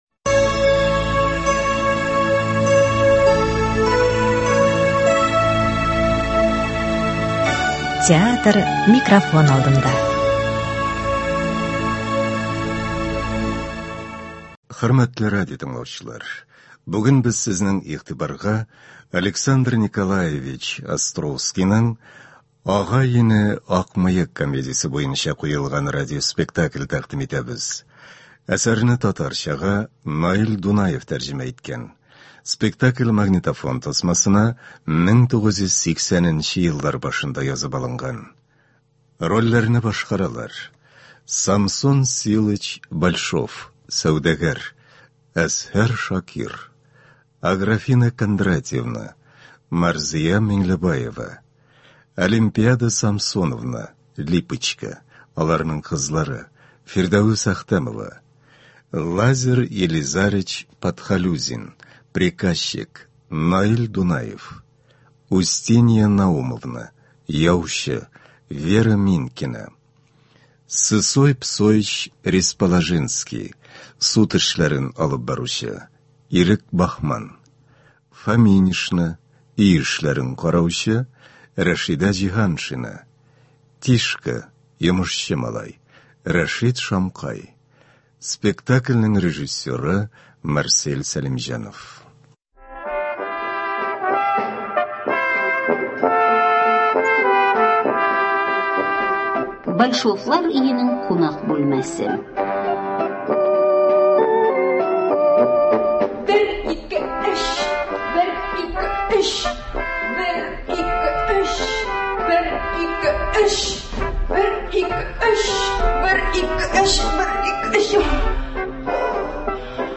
Радиоспектакль.
Спектакльнең режиссеры – СССРның халык артисты Марсель Сәлимҗанов. Спектакль магнитофон тасмасына Татарстан радиосы студиясендә 1980 елларда язып алынган.